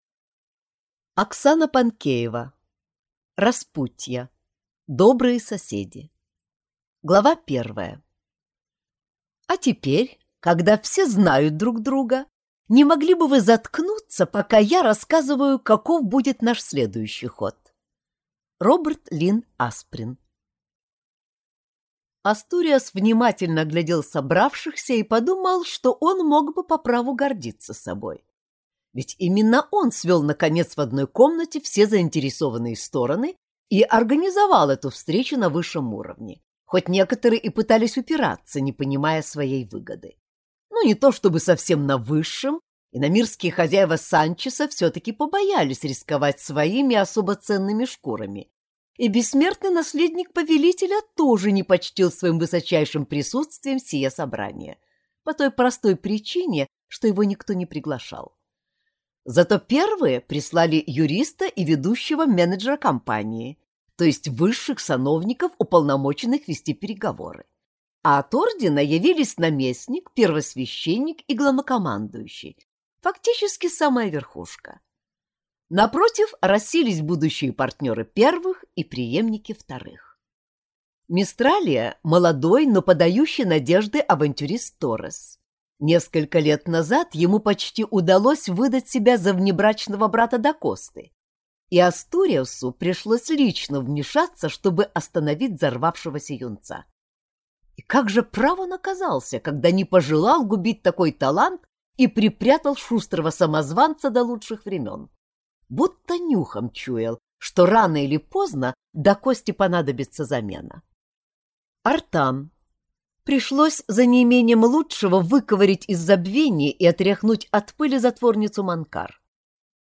Аудиокнига Распутья. Добрые соседи | Библиотека аудиокниг